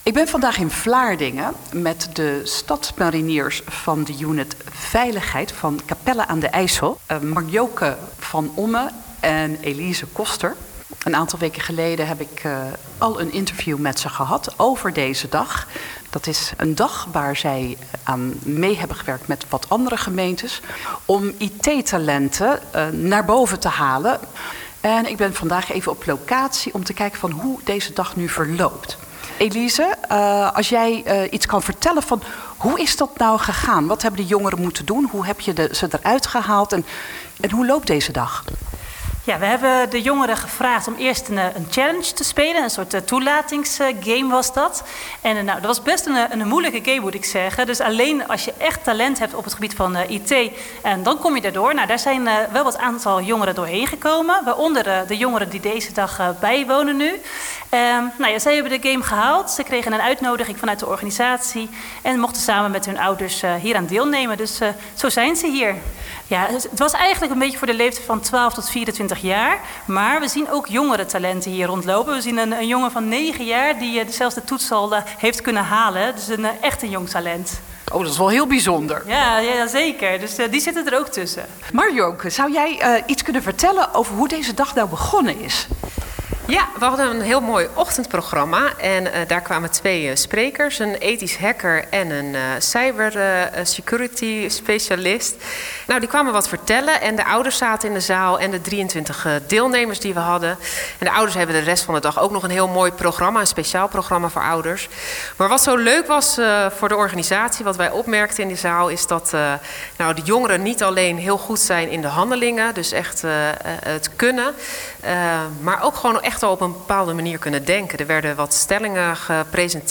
en met een jonge deelnemer.